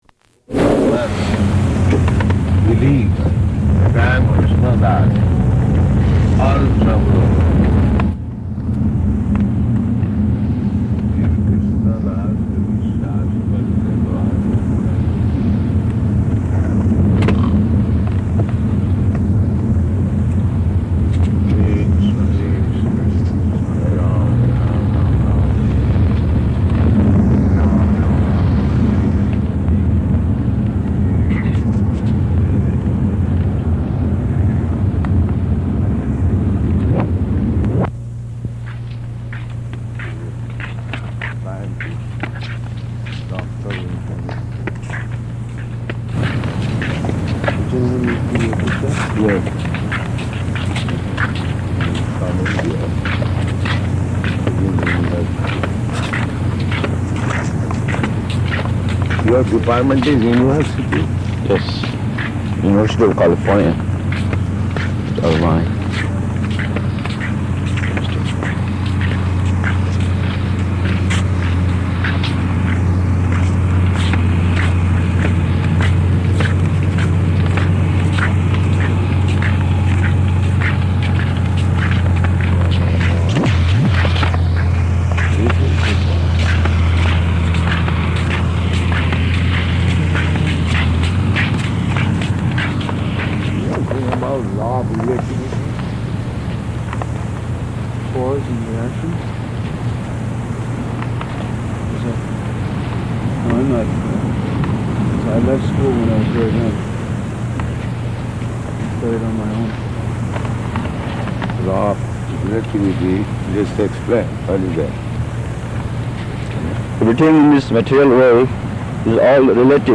Morning Walk
Type: Walk
Location: Los Angeles